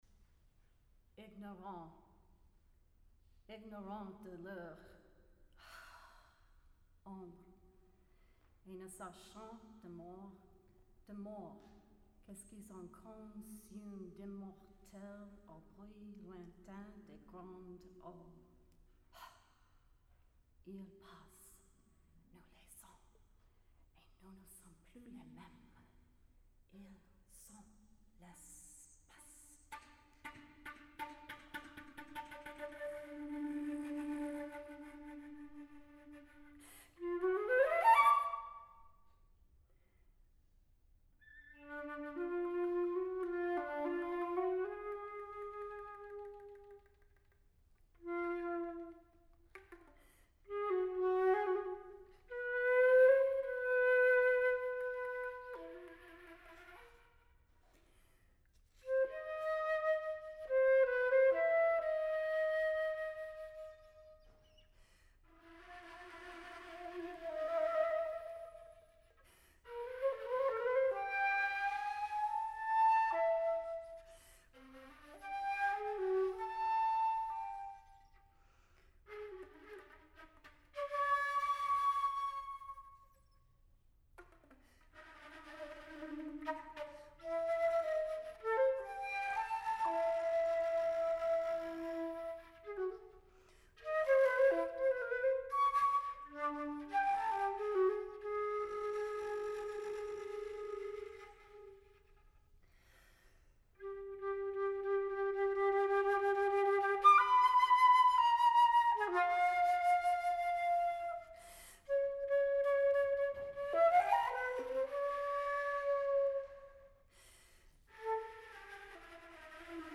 flute
piano
soprano
saxophone
electronics